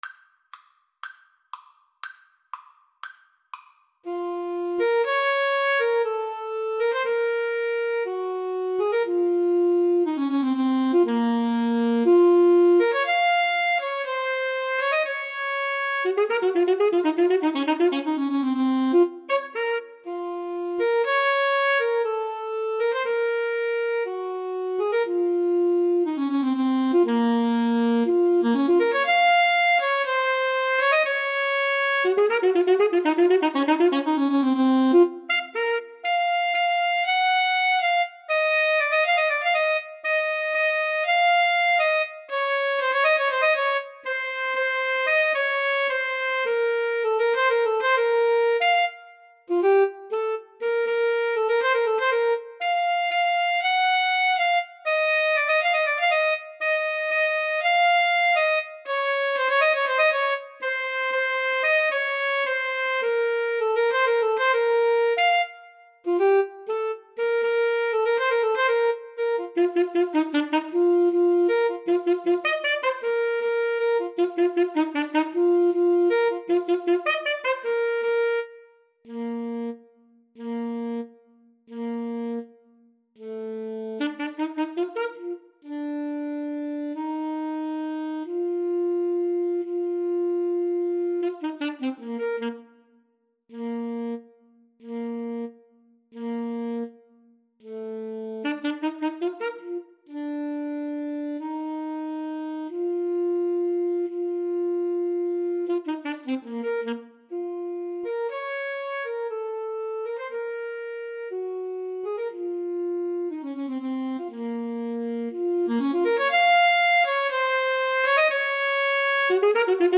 Play (or use space bar on your keyboard) Pause Music Playalong - Player 1 Accompaniment reset tempo print settings full screen
Bb minor (Sounding Pitch) F minor (French Horn in F) (View more Bb minor Music for Alto Saxophone Duet )
2/4 (View more 2/4 Music)
Allegro = 120 (View more music marked Allegro)
Classical (View more Classical Alto Saxophone Duet Music)